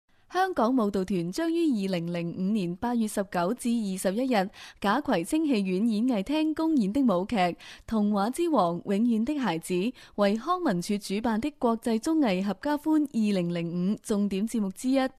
Sprecher chinesisch (Muttersprachler). localization, narration, documentary,advertising etc.
Sprechprobe: Werbung (Muttersprache):